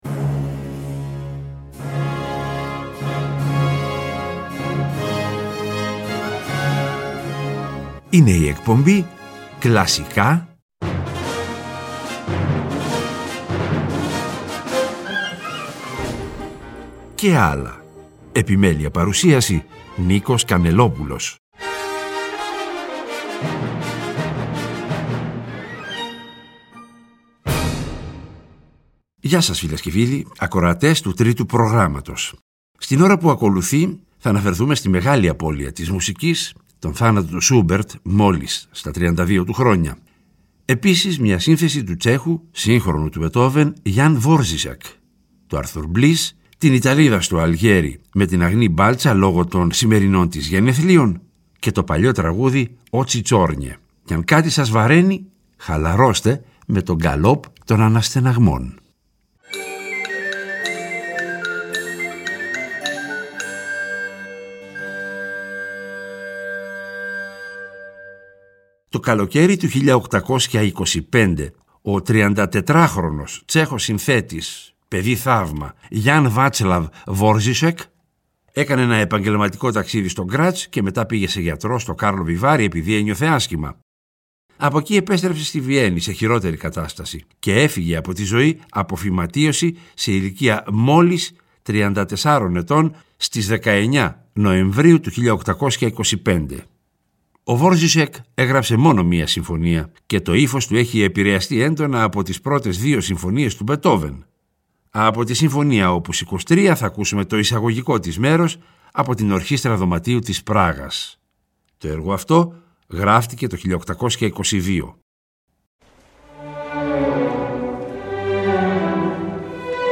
Μεγάλη απώλεια της Μουσικής, ο θάνατος του Σούμπερτ, μόλις στα 32 του χρόνια. Επίσης, μια σύνθεση του Τσέχου, σύγχρονου του Μπετόβεν, Γιαν Βόρζισεκ, του Μπλις, η «Ιταλίδα στο Αλγέρι» με την Αγνή Μπάλτσα -λόγω των γενεθλίων της- και το παλιό ρωσικό (;) «Μαύρα Μάτια».